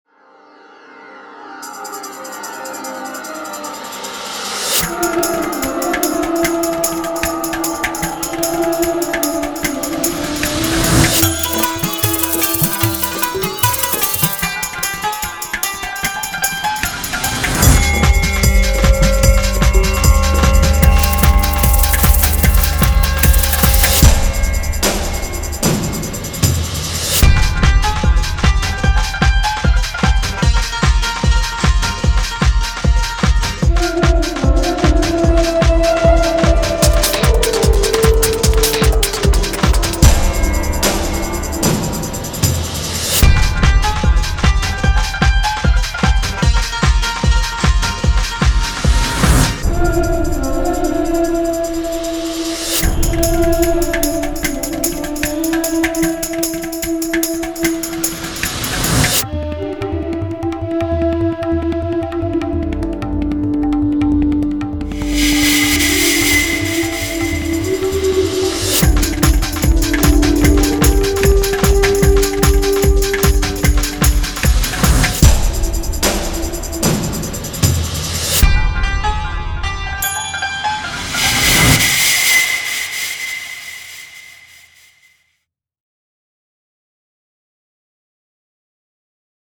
World: Indian Pop